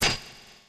PlayerHitWall.ogg